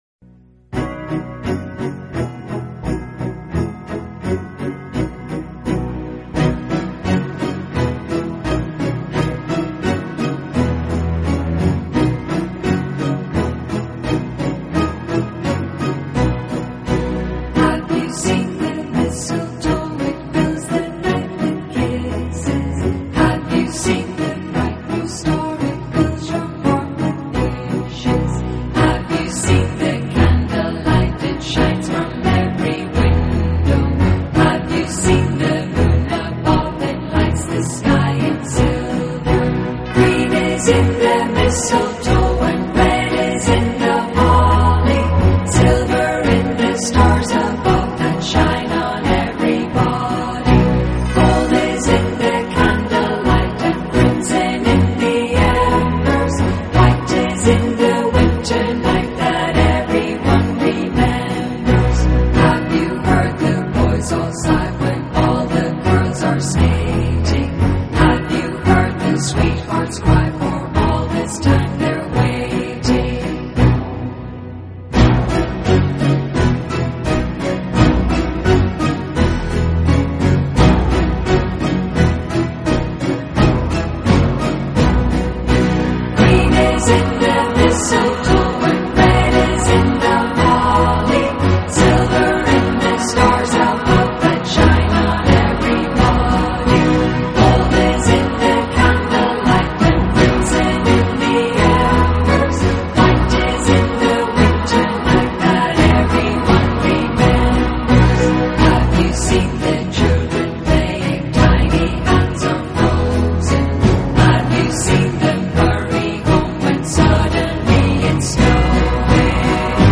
音乐风格 New Age | MP3 LAME VBR 3.97 -V2
Night”这首歌曲则是一首歌咏希望与幸福，洋溢着欢乐气氛的冬季之歌。